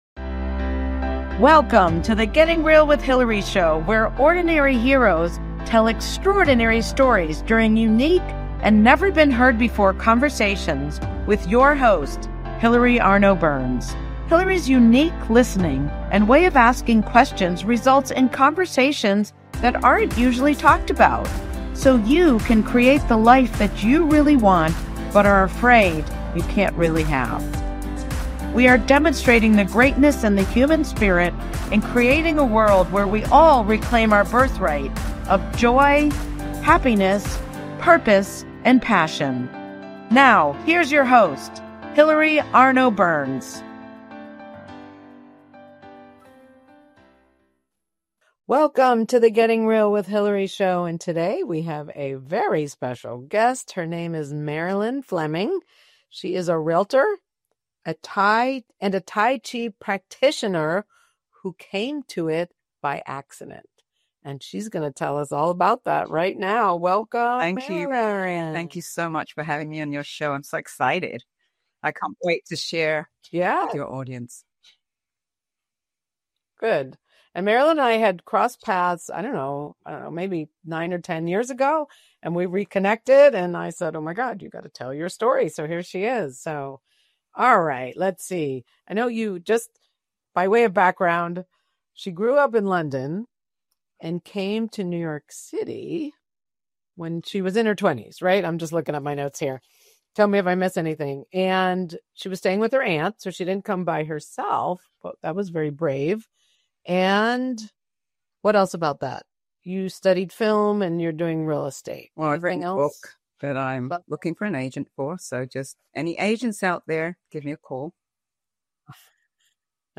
Talk Show
BBS Station 1 Weekly Show